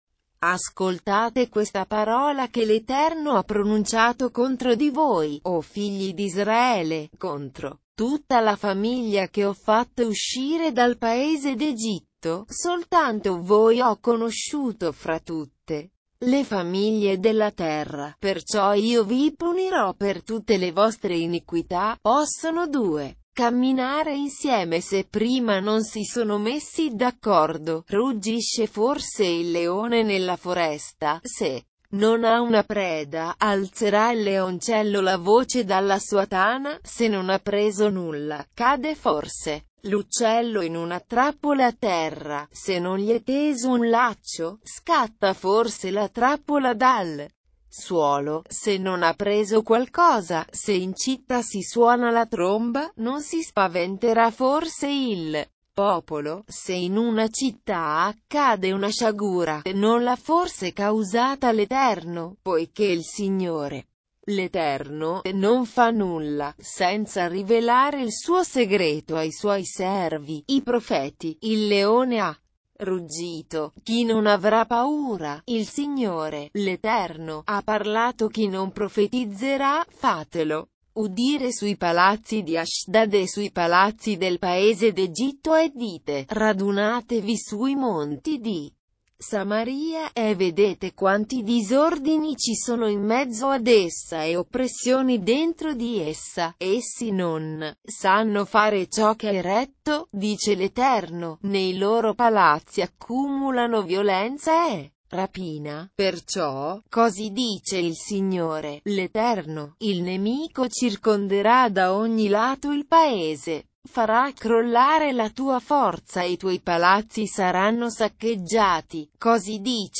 Leitura na versão LND - Italiano